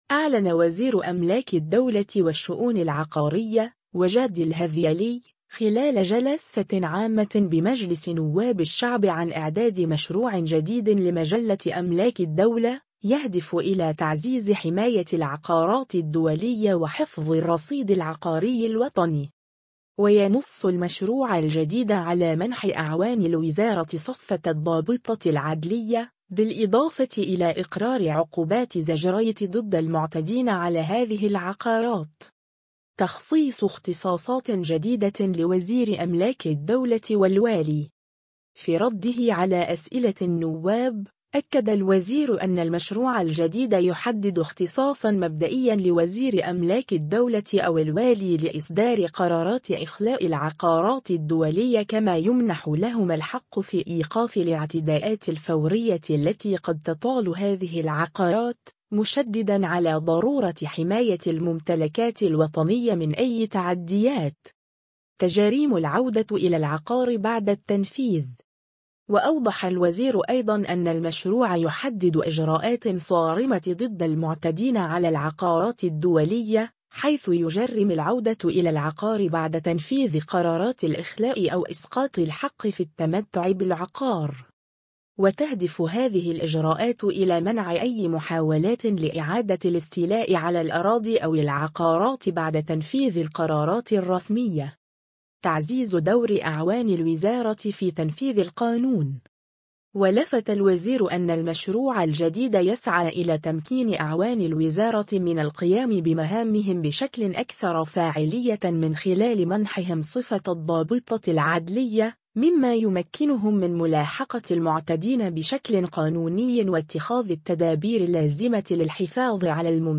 أعلن وزير أملاك الدولة والشؤون العقارية، وجدي الهذيلي، خلال جلسة عامة بمجلس نواب الشعب عن إعداد مشروع جديد لمجلة أملاك الدولة، يهدف إلى تعزيز حماية العقارات الدولية وحفظ الرصيد العقاري الوطني.